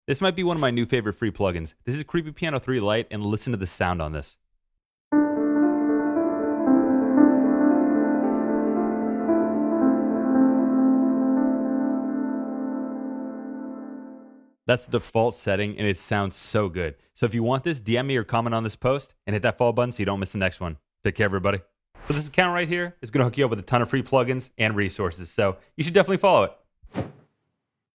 This piano is free and creepy cool